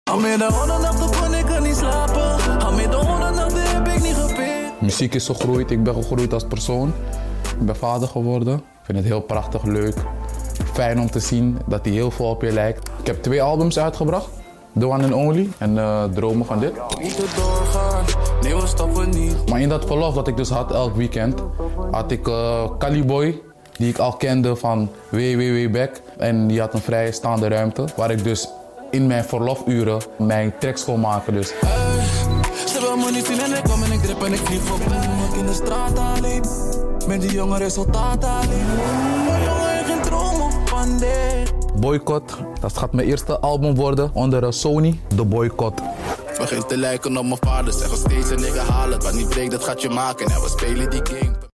101 Barz Interview